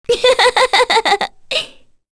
Kirze-Vox_Happy3_kr.wav